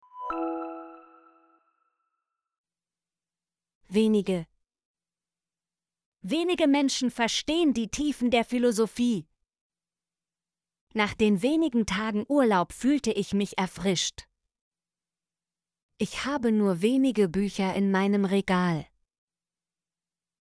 wenige - Example Sentences & Pronunciation, German Frequency List